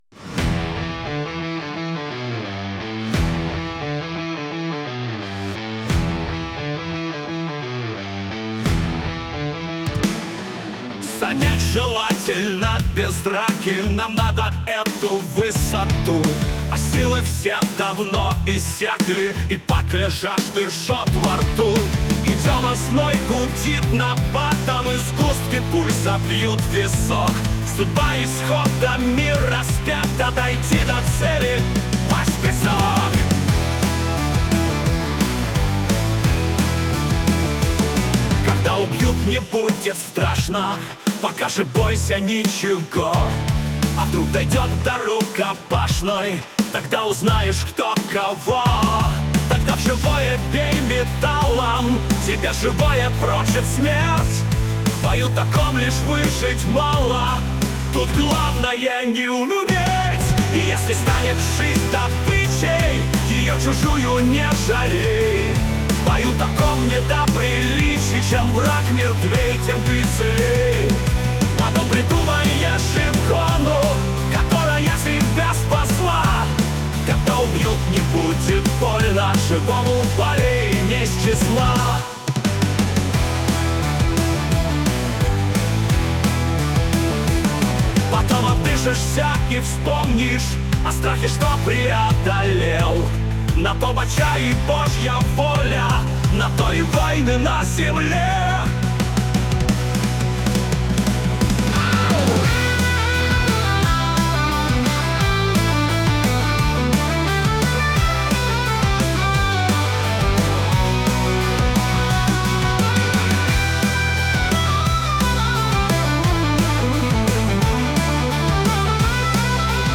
nestx2.mp3 (4326k) Попытка песни ИИ